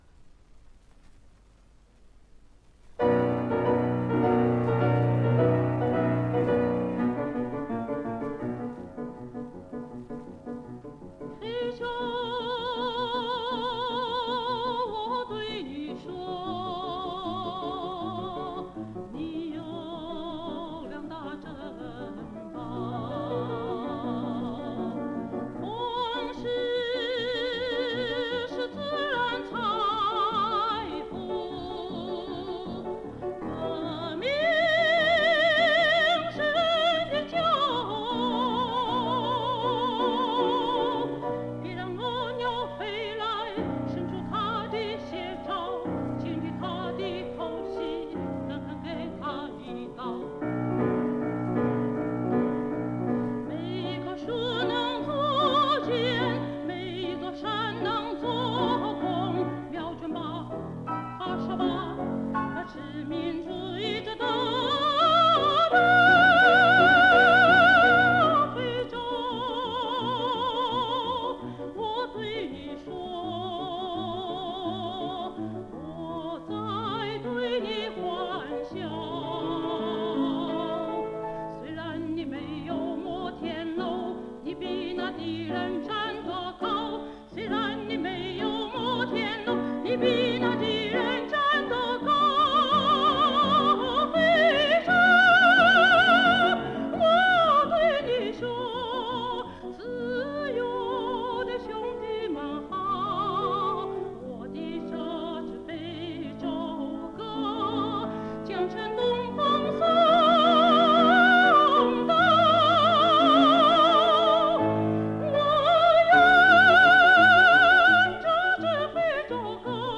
1963年录音